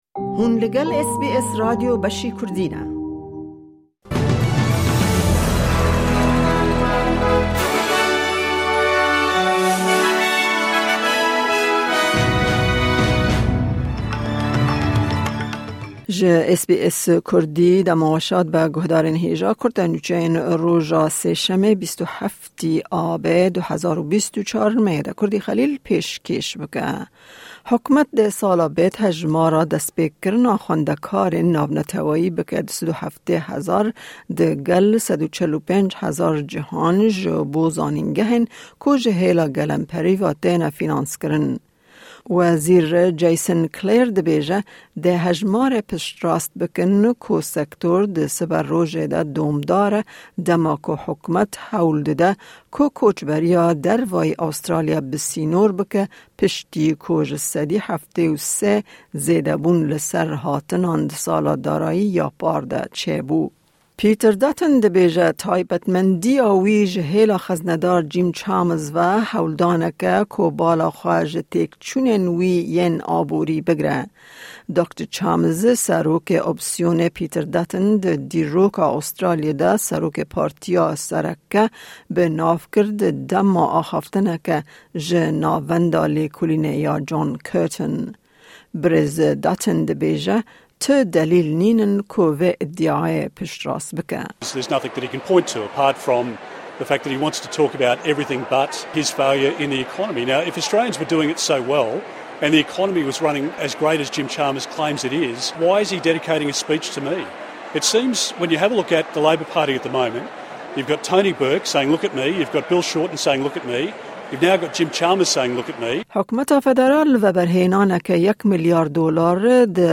Kurte Nûçeyên roja Sêşemê 27î Tebaxa 2024